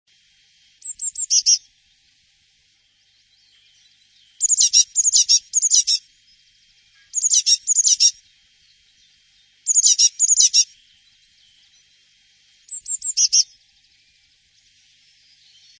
Blaumeise
Anschließend folgt ein typischer Triller in etwas tieferer Tonlage, der meist aus 5 bis 15 kürzeren Elementen besteht. zurück zur Übersicht >
blaumeise.mp3